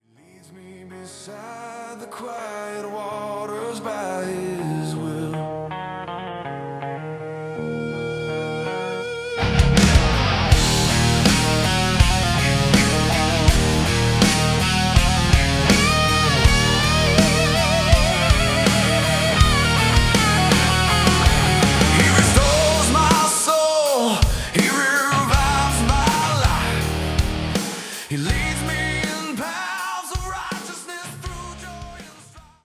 Heavy electric guitar riffs and soaring solos
Driving bass lines that anchor each song
Distinctive rock drumming with dynamic fills
Raw, authentic Southern rock vocals